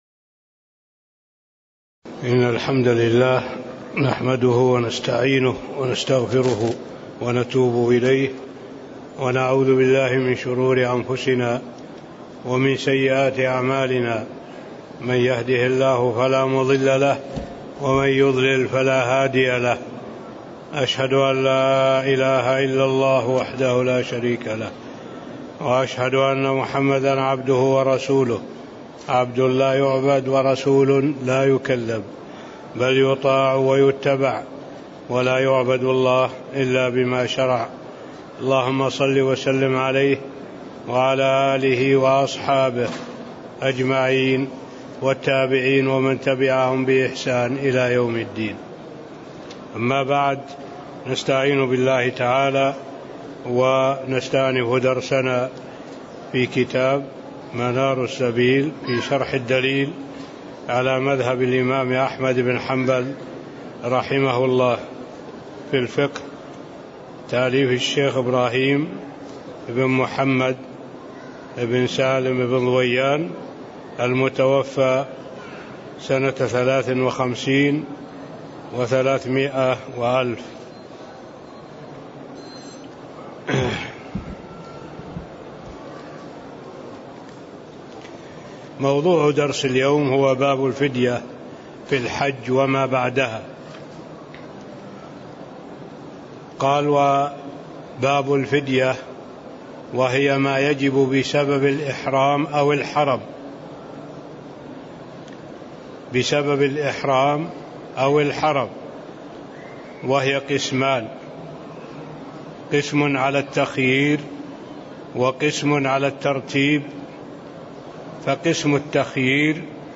تاريخ النشر ٢٣ شوال ١٤٣٦ هـ المكان: المسجد النبوي الشيخ